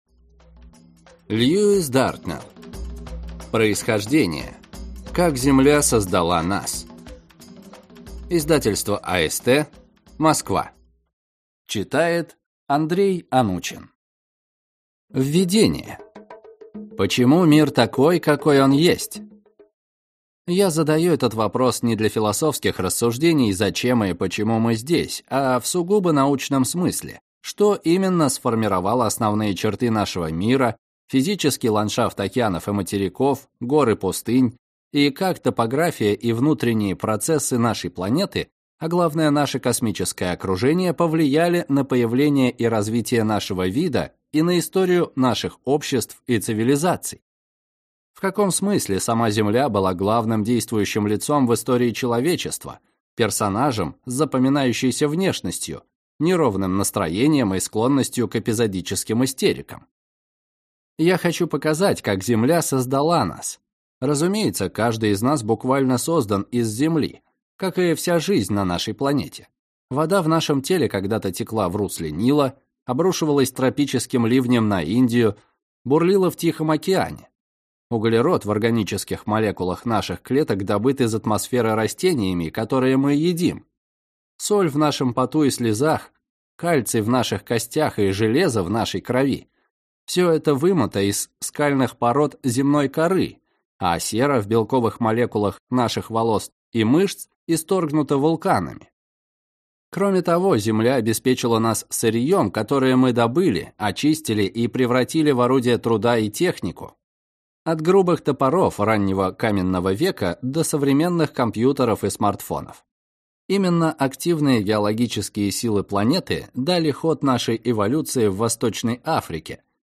Аудиокнига Происхождение. Как Земля создала нас | Библиотека аудиокниг